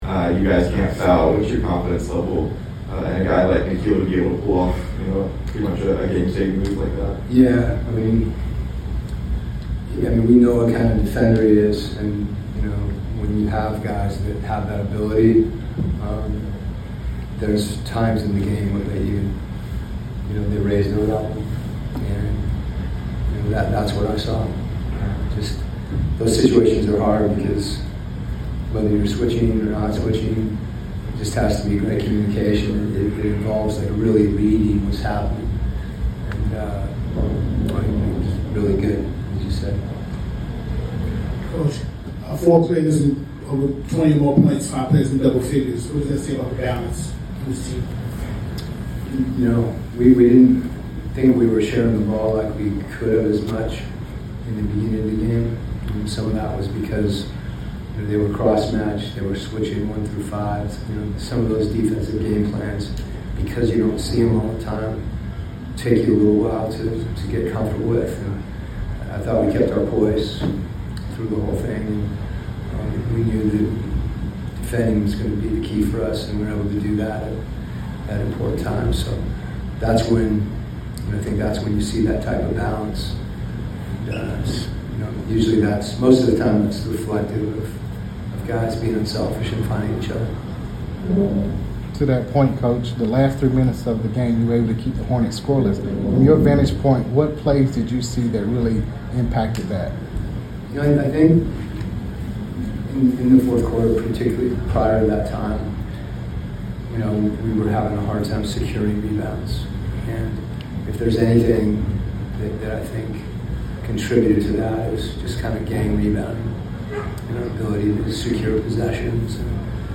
Atlanta Hawks Coach Quin Snyder Postgame Interview after defeating the Charlotte Hornets at State Farm Arena.